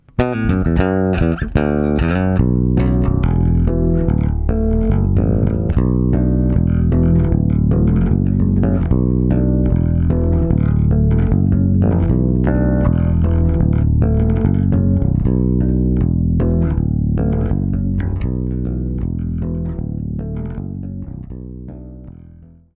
Jedes Mal, wenn ich überlege meinen RBX 775 zu inserieren, nehme ich den in die Hand, spiele ein bisschen und bin mega froh, den nicht verkauft zu haben. xD Geilste Farbe und einfach ein phantastisches Instrument.